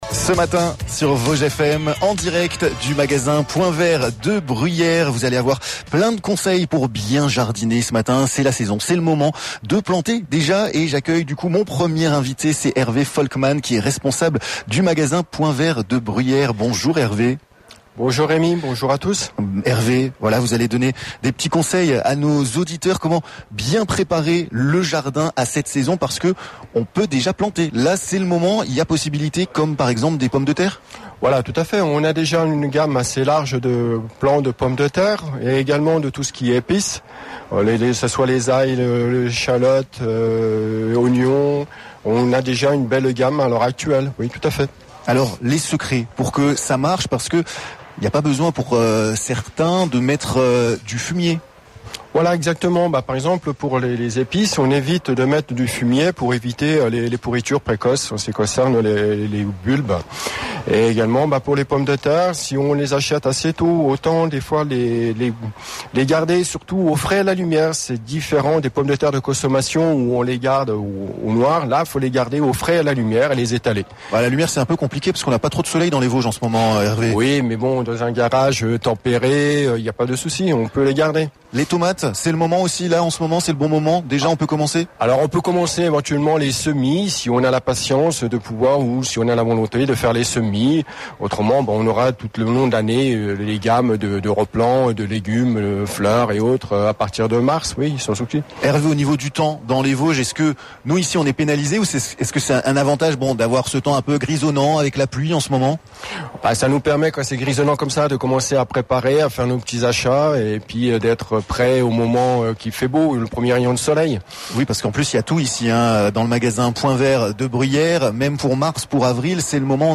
Bruyères: revivez le live chez Point Vert, la nature c'est leur métier!